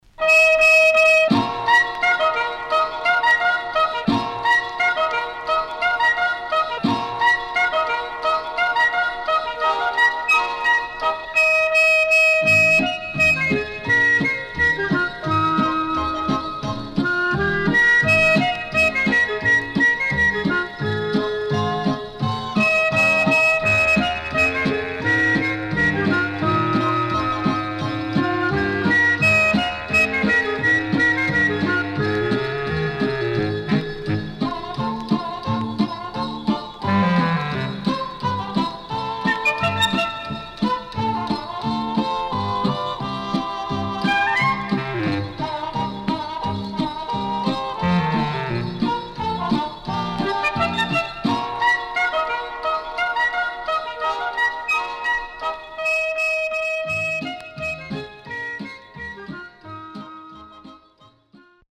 HOME > Back Order [VINTAGE LP]  >  CALYPSO
SIDE A:所々ノイズ入ります。
SIDE B:少しノイズ入りますが良好です。